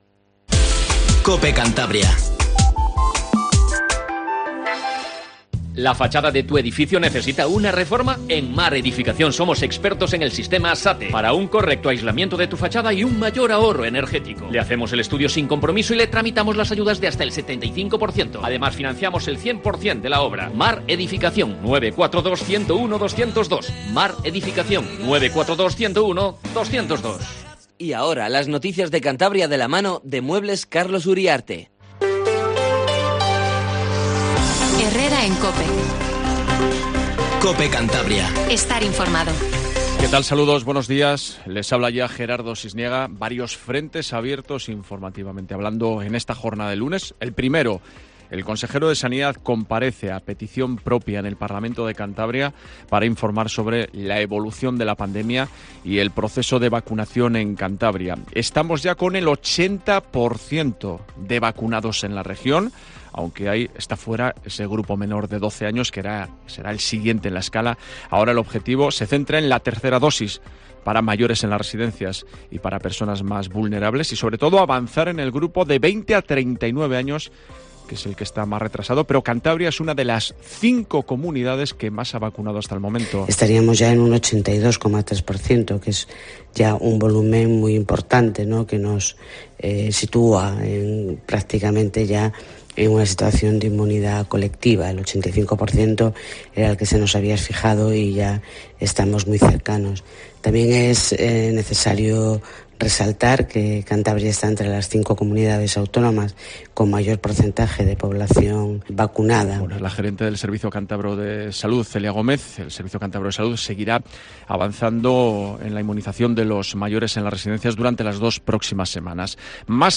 Informativo Matinal COPE CANTABRIA